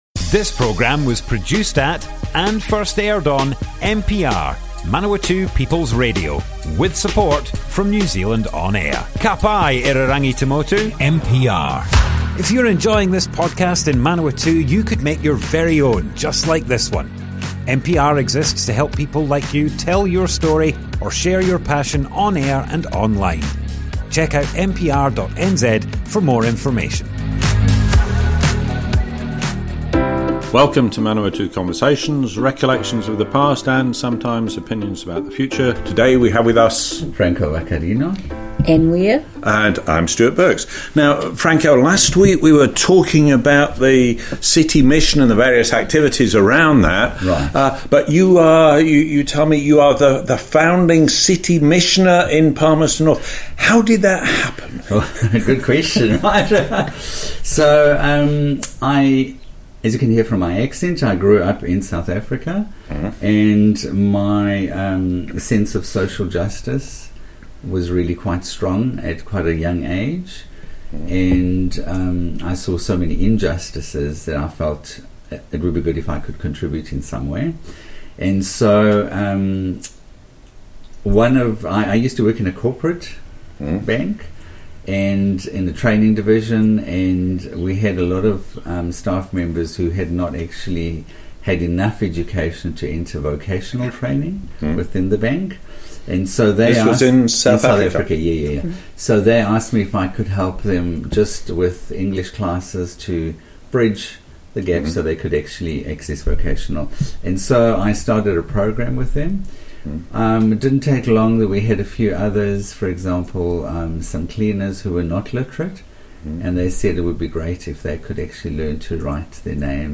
Manawatu Conversations More Info → Description Broadcast on Manawatu People's Radio, 25th November 2025.
oral history